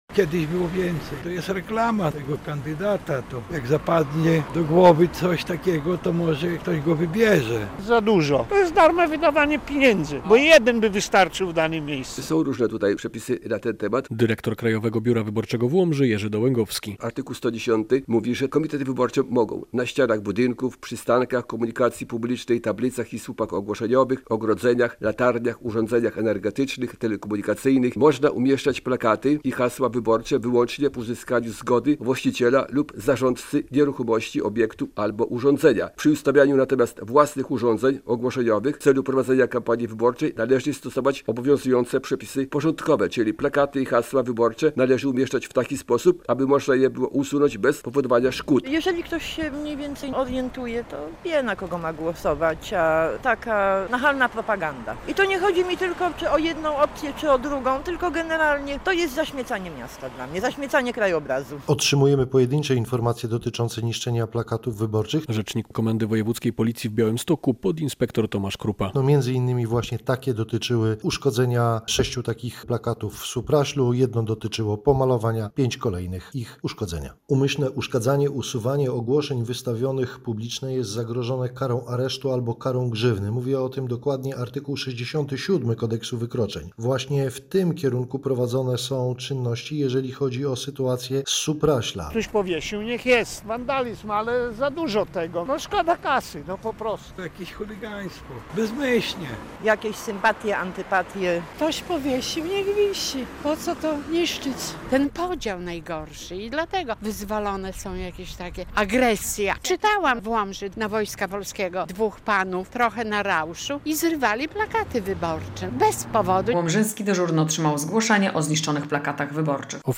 Część tych plakatów jest jednak niszczona. Dlaczego tak się dzieje - nasz reporter pytał o to mieszkańców Łomży.